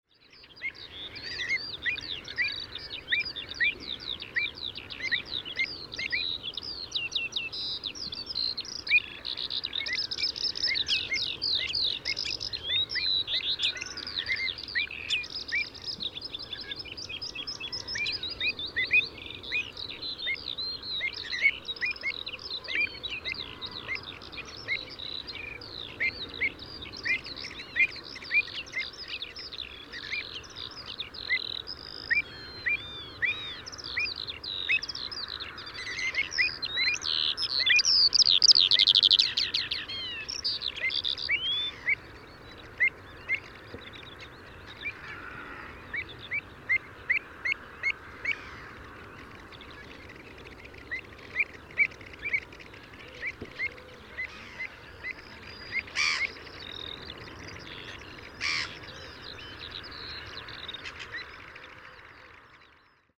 PFR07299, Pied Avocet Recurvirostra avosetta, alarm calls (Common Pheasant at nestside), Cley Marshes, UK